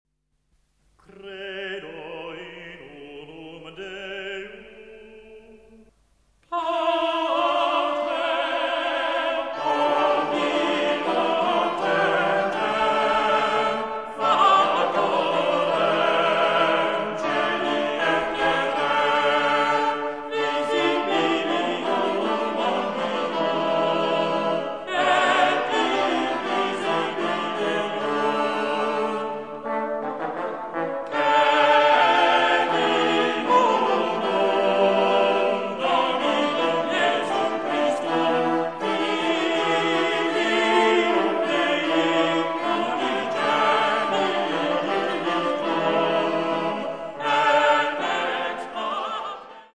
(1300-1377) Het is een vierstemmige zetting van het ordinarium.
• de vierstemmige zetting (in die tijd ongebruikelijk)